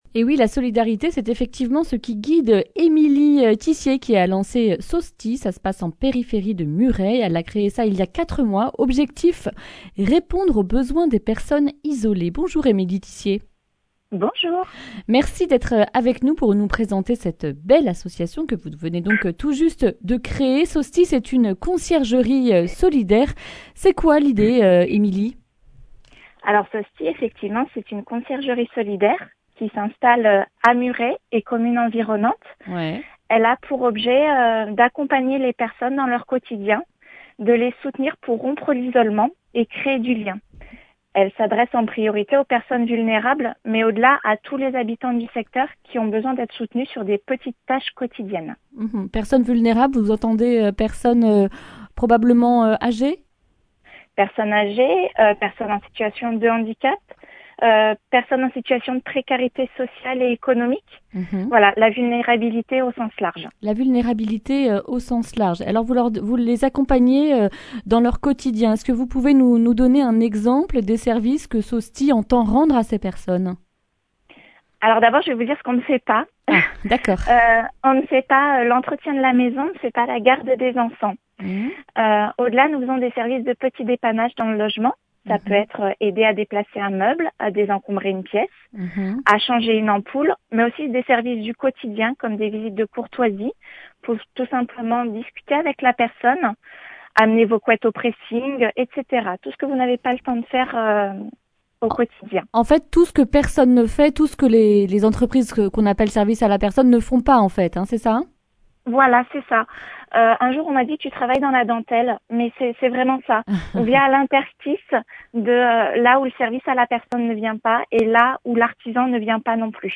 lundi 14 juin 2021 Le grand entretien Durée 11 min